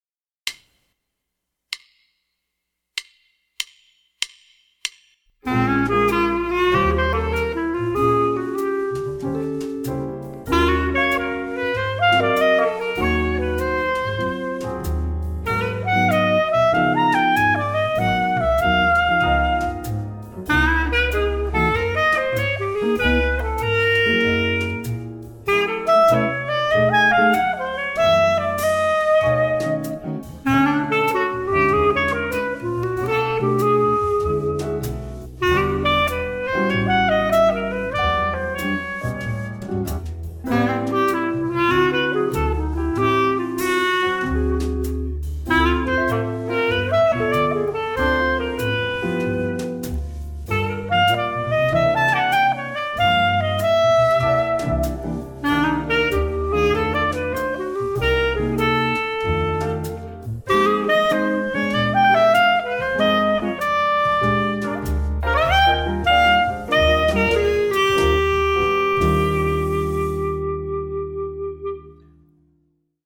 DESOTONotice how the triplets give this variation a loping, rolling momentum, like cruising down a 2-lane blacktop in a ’56 Desoto.
And you discover that different notes gain prominence as a result of the shifting rhythms.
By the way, I recorded all of these tracks without charts.